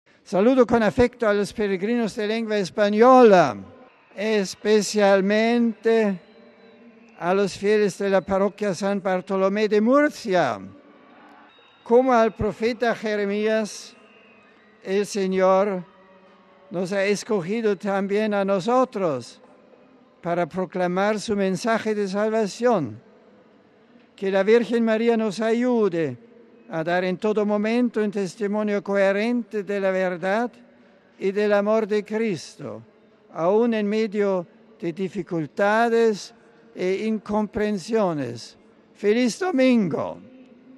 En el marco de la oración mariana del Ángelus, Benedicto XVI ha denunciado con contundencia el regreso de la violencia que ha vuelto a ensangrentar el Líbano.
Entre los saludos en distintas lenguas, Benedicto XVI se ha dirigido también a los peregrinos de nuestra lengua.